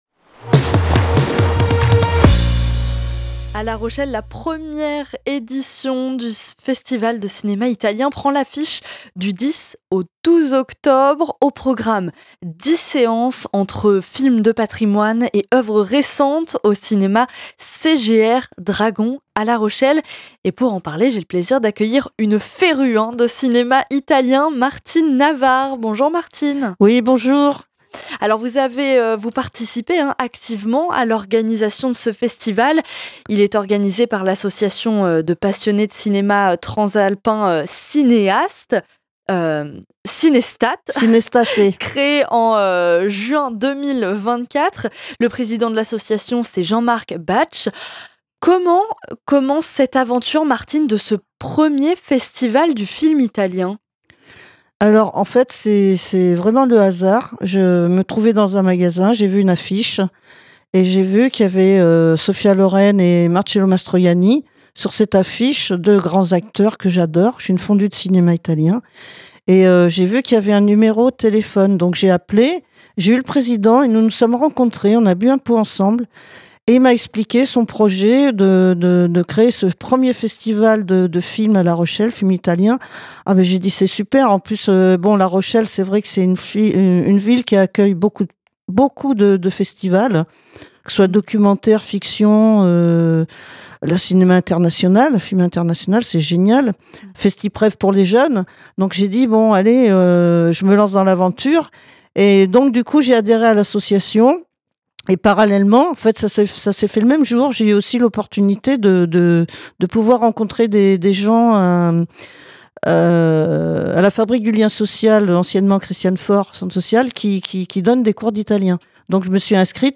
LAVAGUEMATINALE-ITW FESTIVAL FILM ITALIEN.mp3